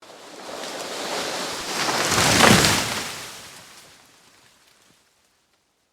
Free SFX sound effect: Tree Fall 3.
Tree Fall 3
Tree Fall 3.mp3